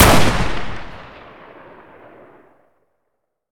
Wpn_trailcarbine_fire_2d_02.ogg